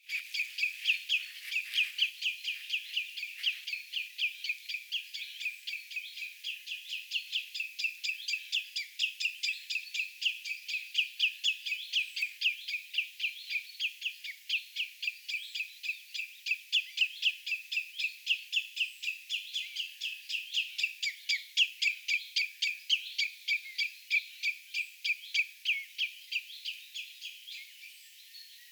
punajalkaviklon huomioääntelyä
punajalkaviklon_huomioaantelya.mp3